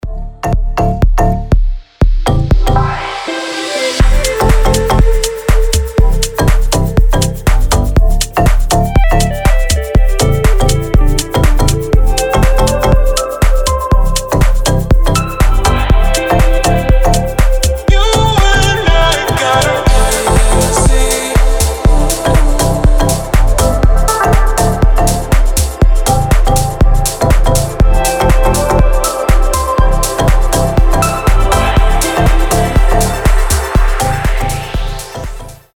• Качество: 320, Stereo
deep house
атмосферные
Electronic
future house
Chill